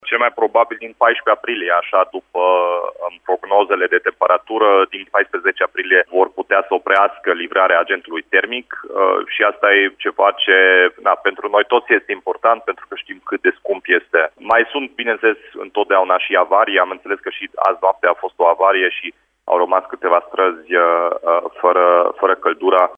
Primarul Timișoarei a reiterat, la Radio Timișoara, că doar în primele trei luni ale anului au fost virați aproape 100 de milioane de lei către Colterm.